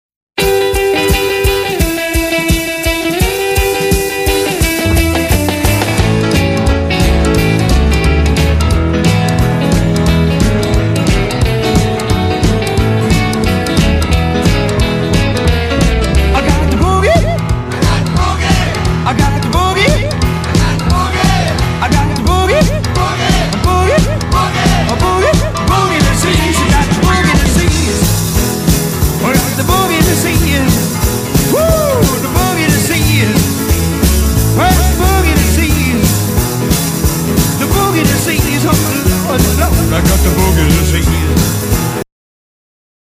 Genre: Rockabilly, Christmas